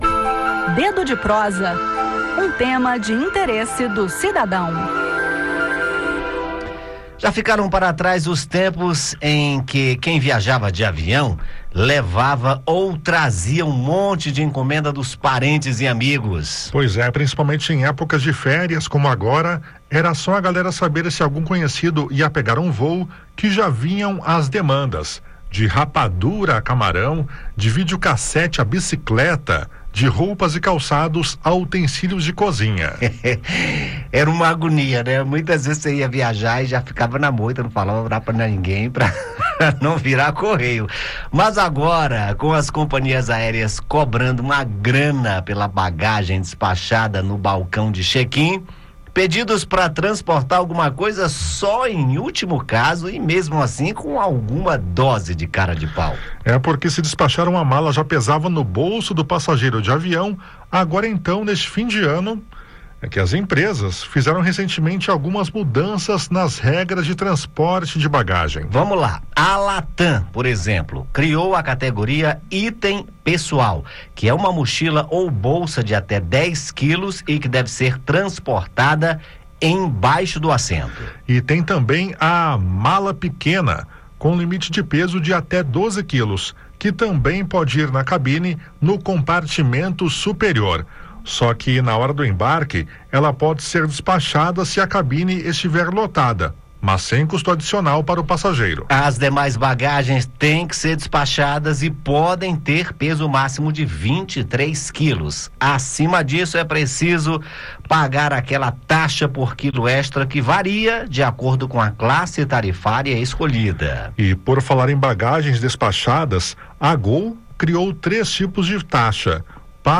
O bate-papo desta quarta-feira (18) é sobre a cobrança pela bagagem dos passageiros que viajam de avião.